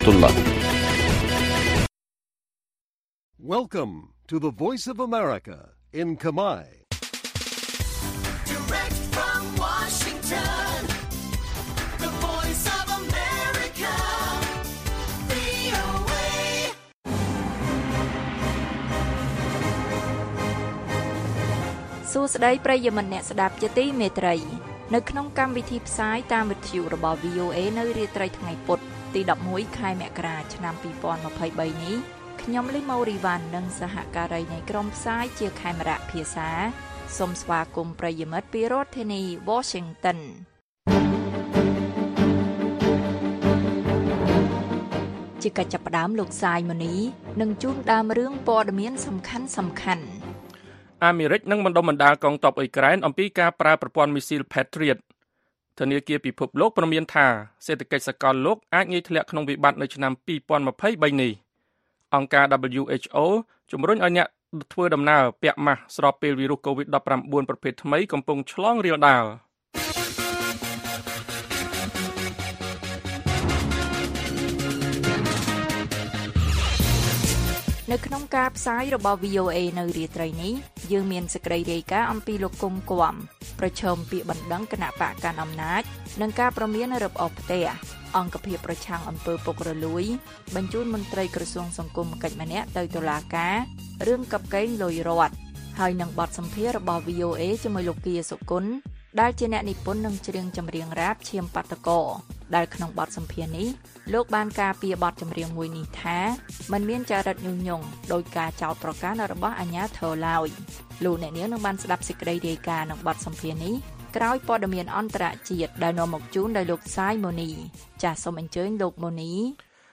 អាមេរិកនឹងបណ្ដុះបណ្ដាលកងទ័ពអ៊ុយក្រែនអំពីការប្រើប្រព័ន្ធមីស៊ីល Patriot។ បទសម្ភាសន៍ VOA